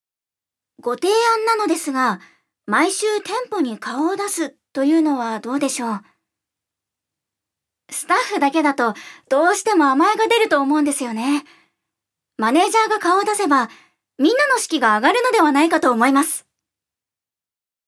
預かり：女性
音声サンプル
セリフ１